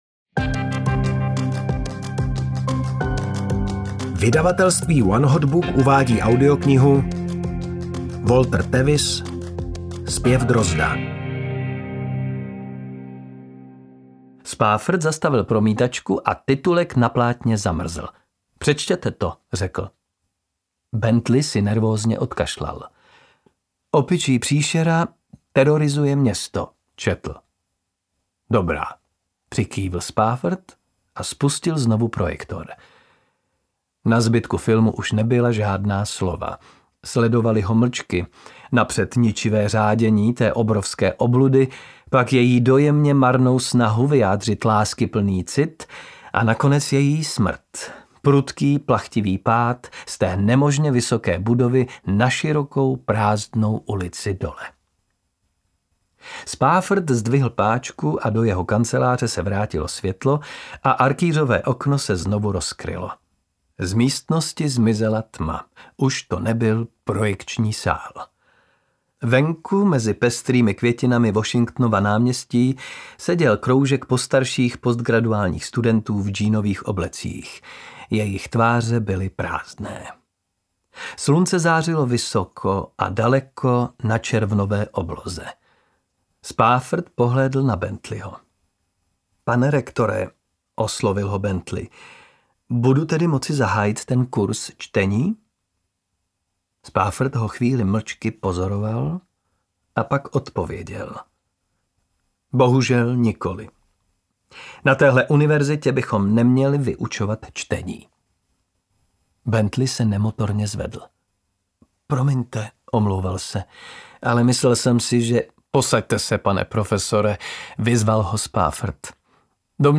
Ukázka z knihy
• InterpretKateřina Winterová, Martin Písařík, Tomáš Pavelka